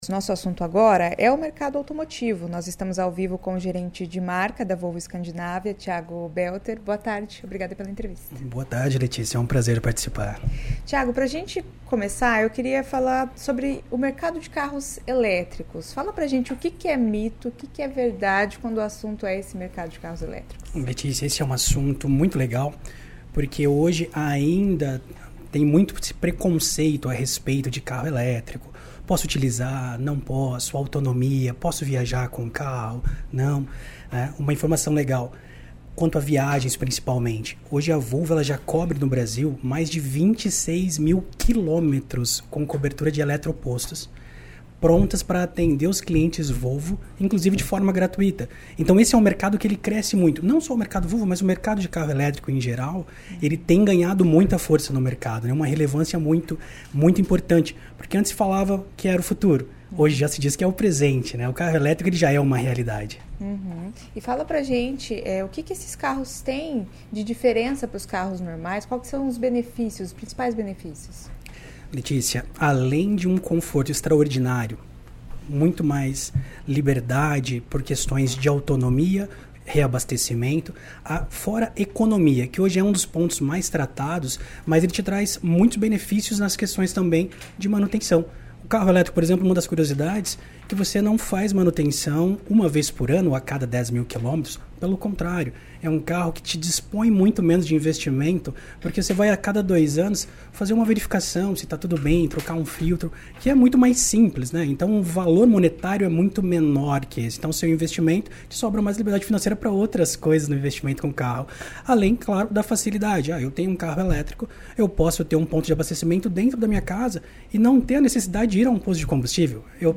Quais são os mitos e verdades em relação aos carros elétricos? Quais as expectativas do mercado? Ouça a entrevista.